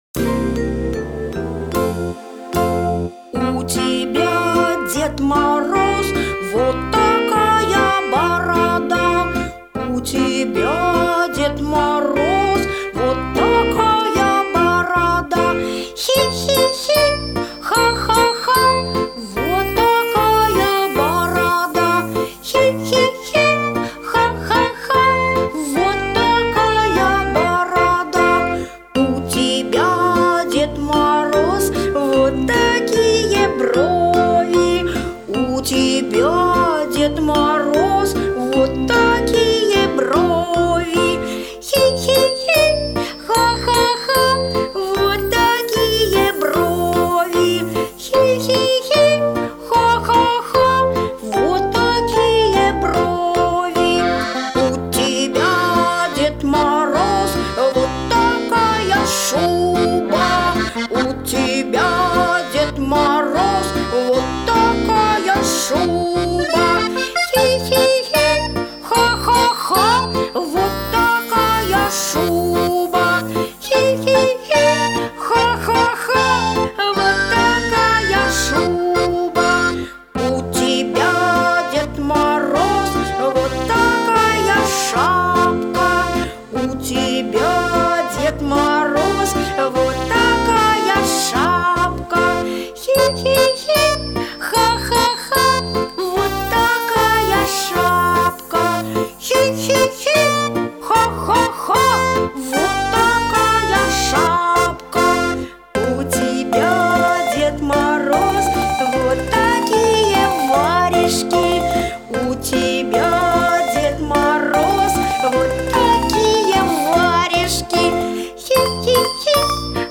для детского сада